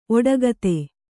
♪ oḍagate